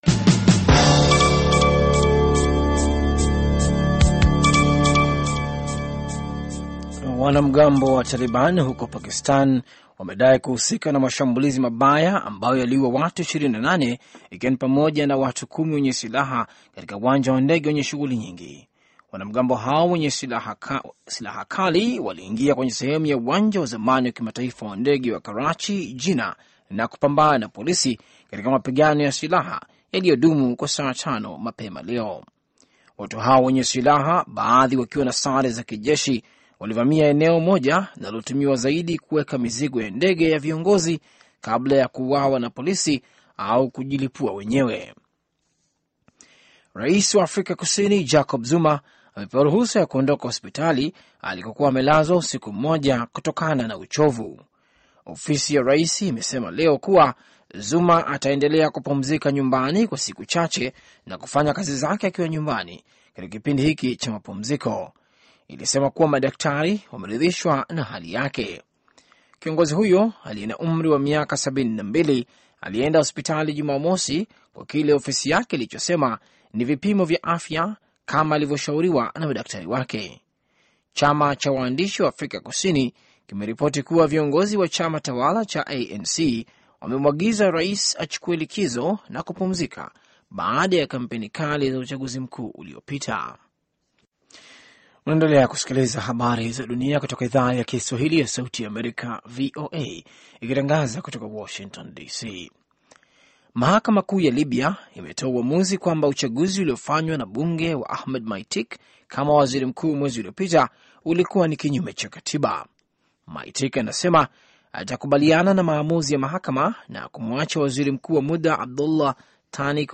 Taarifa ya Habari VOA Swahili - 4:40